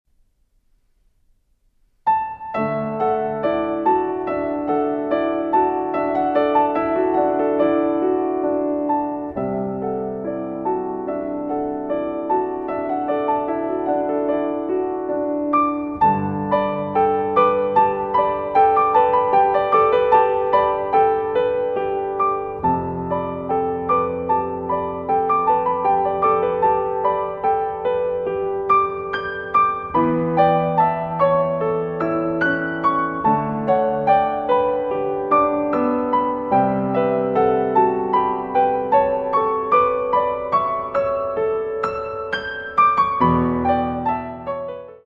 Moderato con anima